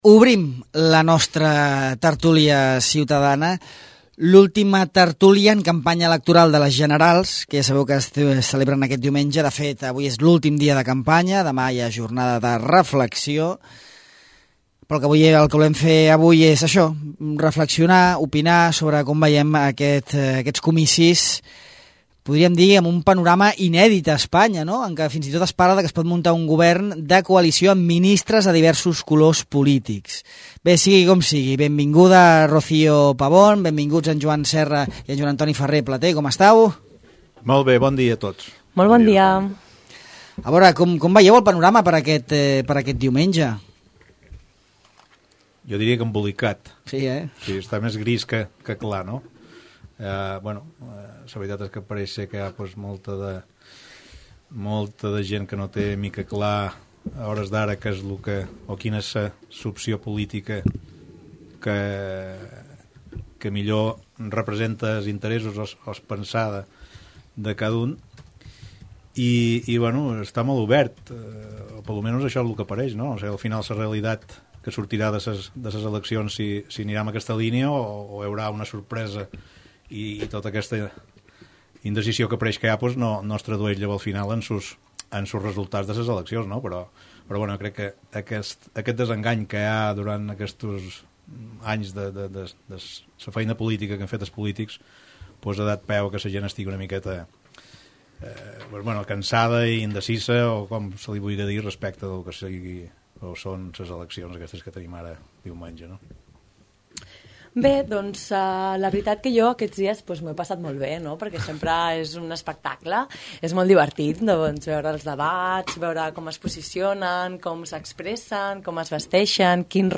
La tertúlia ciutadana imagina les possibles coalicions que poden sorgir de les eleccions generals